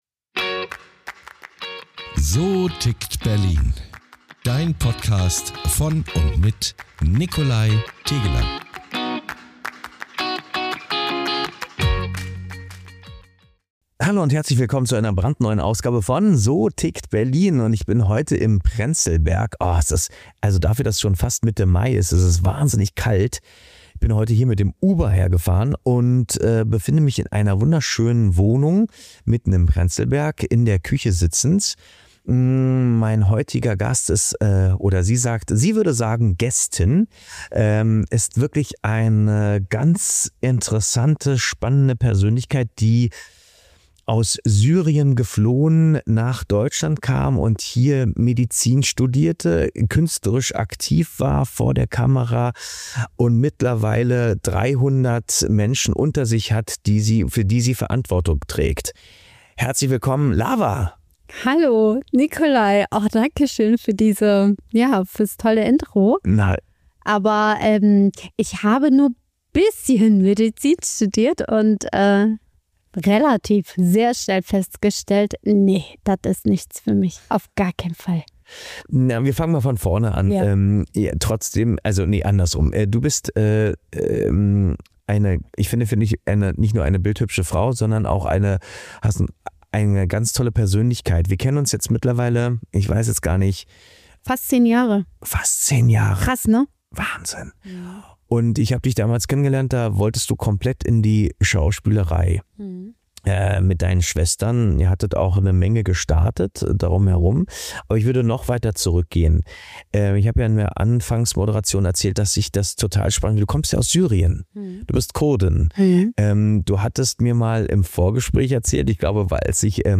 Ein ehrliches, bewegendes und motivierendes Gespräch über Chancen, Rückschläge und die Kraft, niemals aufzuhören, an sich zu glauben.